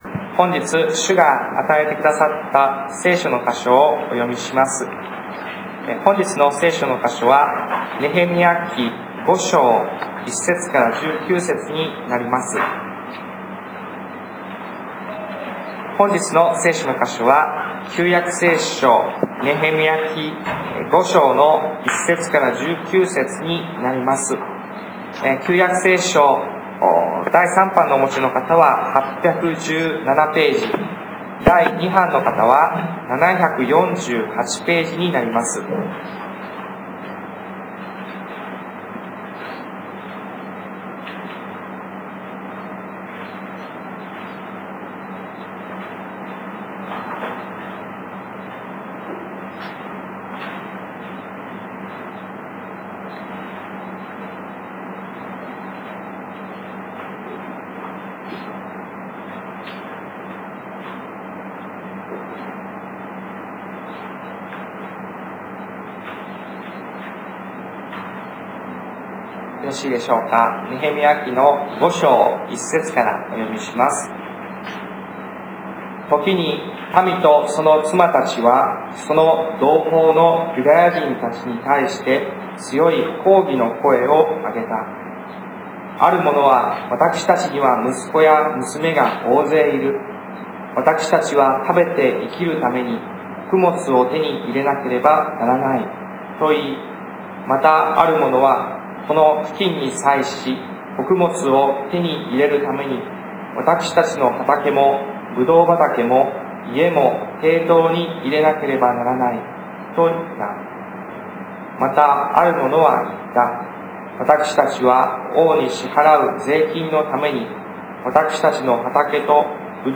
礼拝メッセージ集 - タイ聖書福音教会
2013年2月3日メッセージ